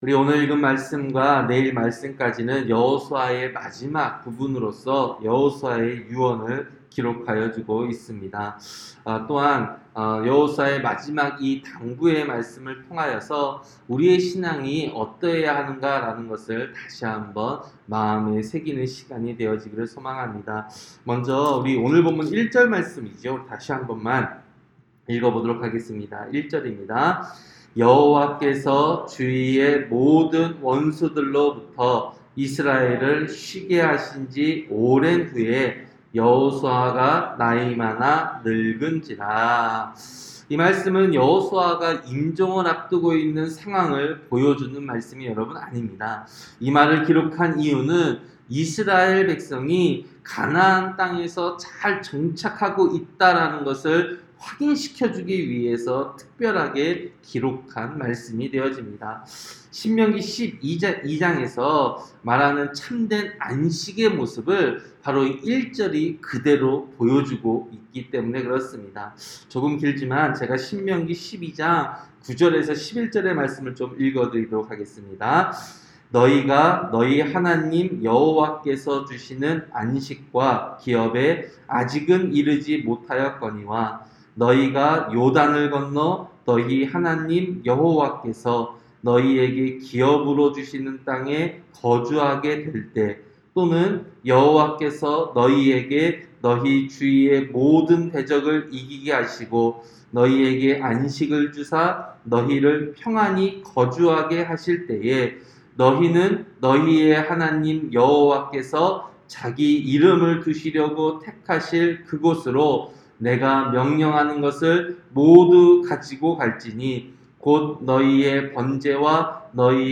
새벽설교-여호수아 23장